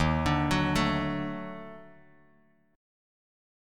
D#mM7b5 chord